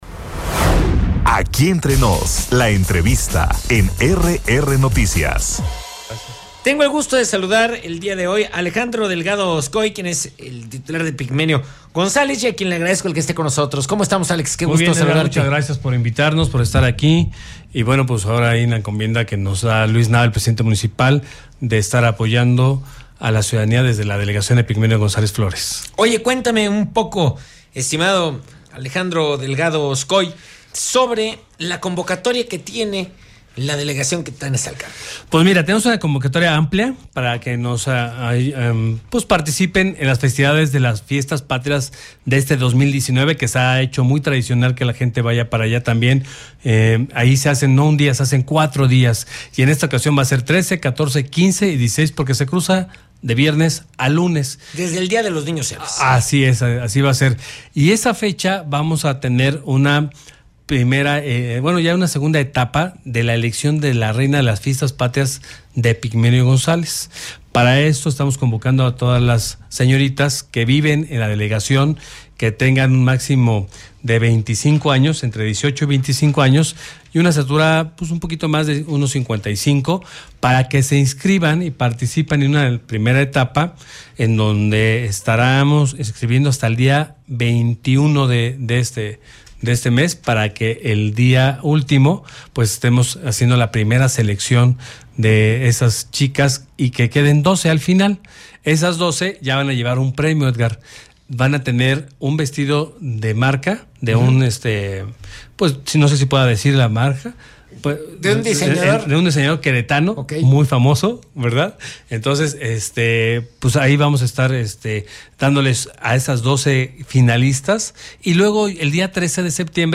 Entrevista con el delegado de Epigmenio González, Alejandro Delgado Oscoy, por la segunda emisión de RR Noticias
ENTREVISTA-ALEJANDRO-DELGADO-OSCOY-DELEGADO-DE-EPIGMENIO-GONZALEZ.mp3